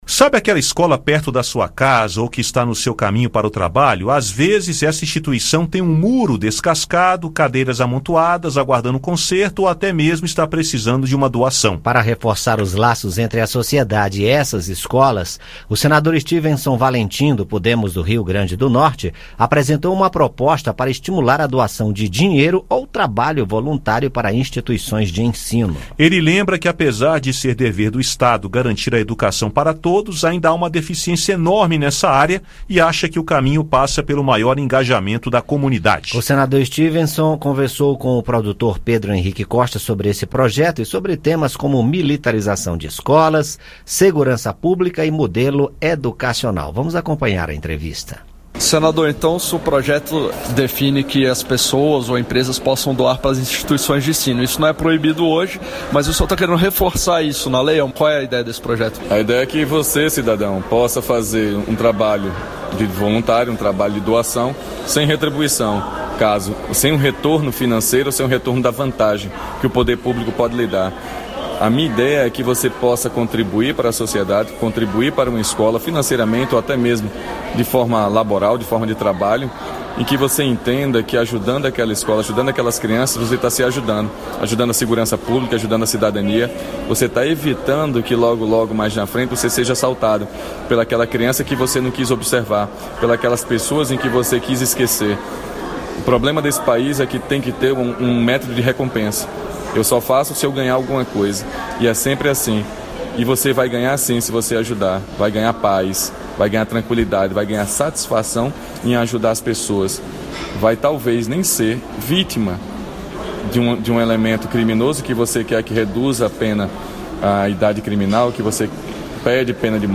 E nesta segunda-feira (30) conversamos sobre o tema com o autor da proposta, senador Styvenson Valentim (Podemos-RN), que também falou de militarização de escolas, segurança pública e modelo educacional. Ouça o áudio com a entrevista.